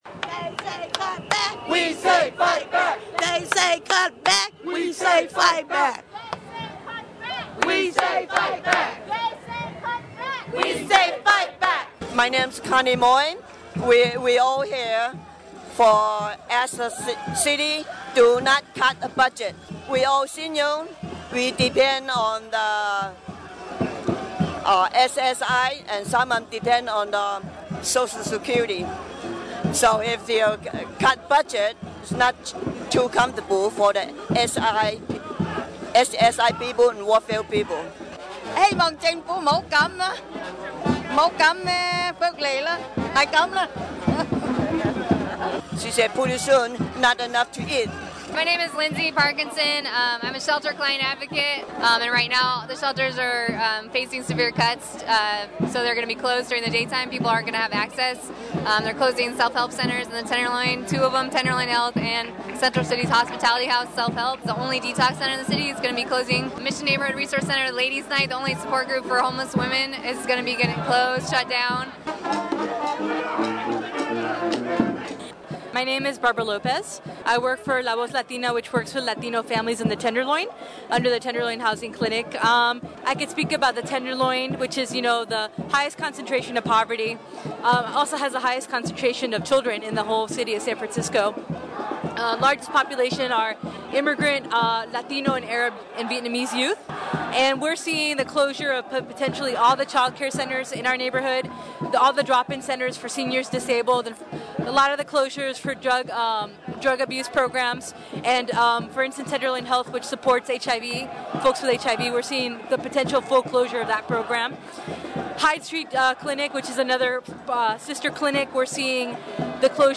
§Vox Pop from the Demonstration
6 minute collage of voices from the demonstration
sf_budget_demo_vox_pop.mp3